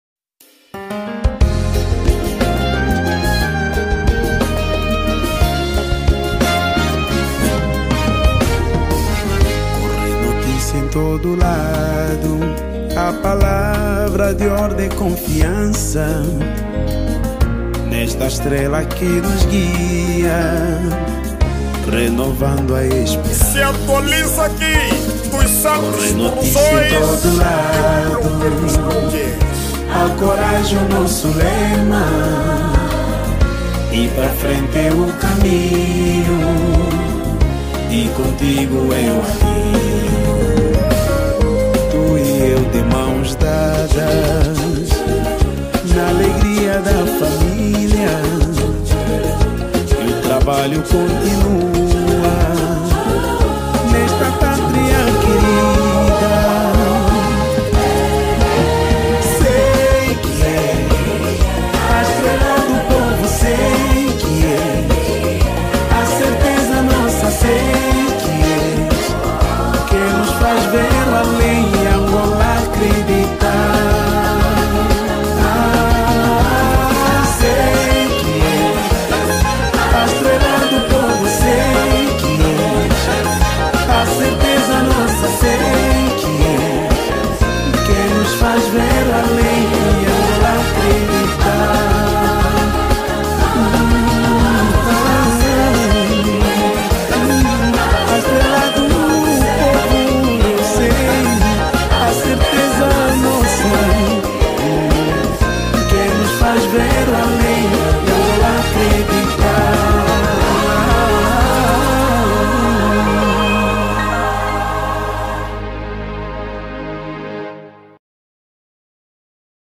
Categoria:  Semba